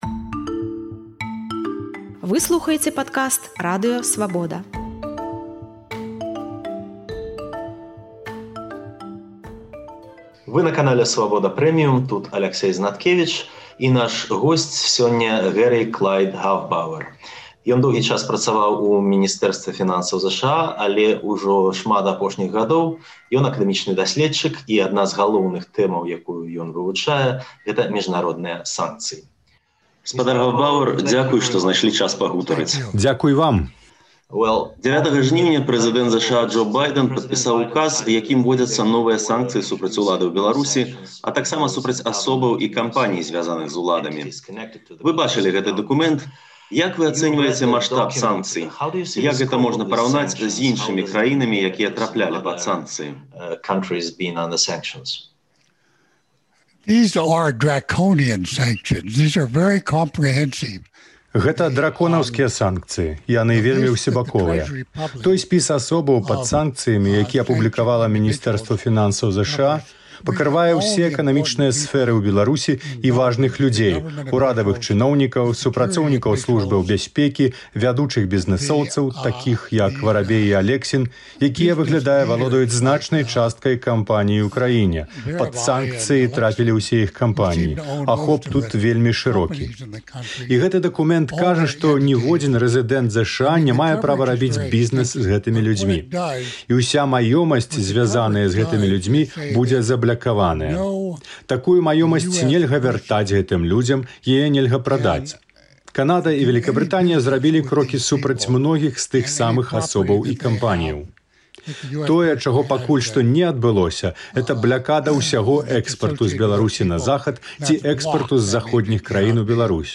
У гутарцы з Радыё Свабода ён расказаў, як ацэньвае ўказ прэзыдэнта ЗША Байдэна датычна Беларусі.